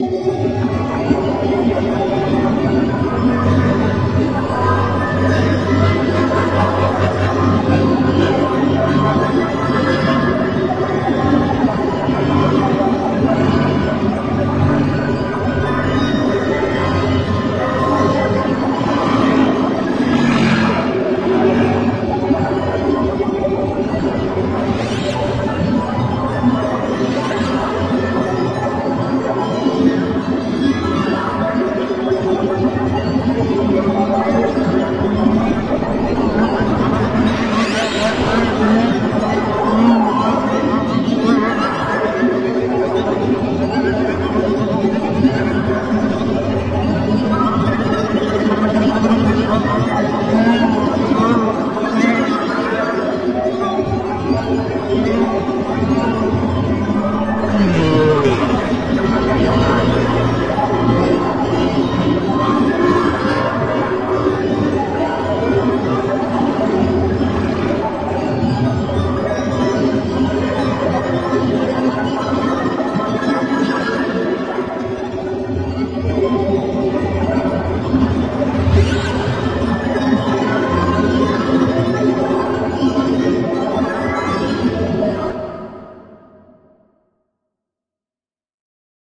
描述：非常严重的处理过的声音。基本的声音是重叠的随机计算机生成的音符，用过滤器和混响效果处理。